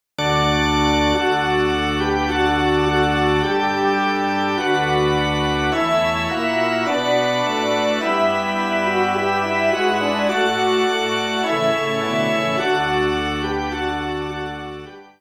Genre :  Religieux
ENSEMBLE